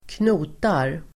Uttal: [²kn'o:tar]